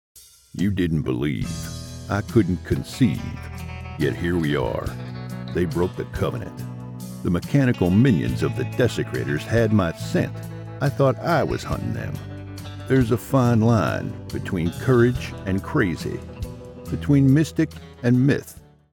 I have a deep, rich, resonant voice perfect for any type of voice over you need.
Space Cowboy for a video drama Southern accent, Deep voiced, Smooth, Cool
A Source-Connect equipped professional Whisper Room sound booth.